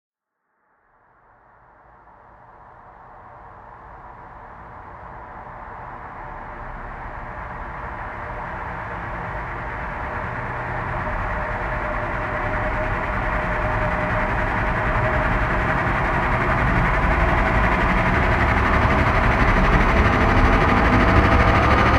Долгий звук телепортации или возрождения